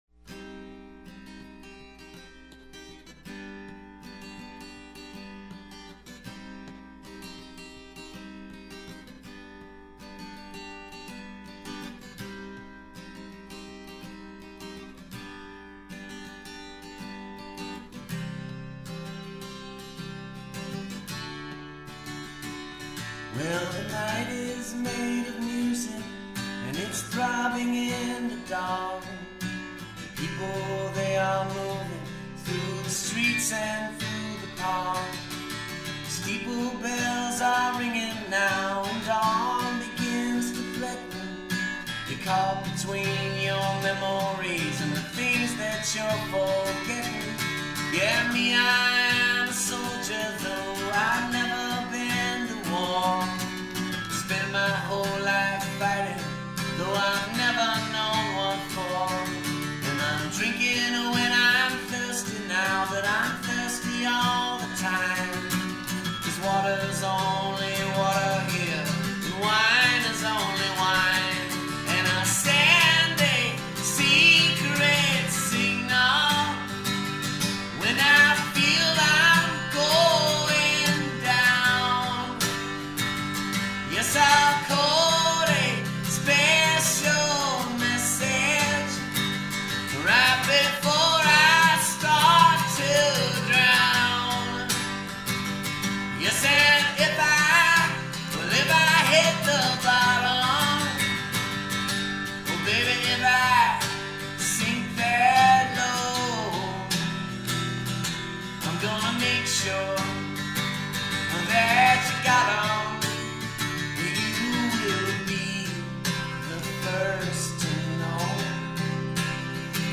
We did only one or two takes per song.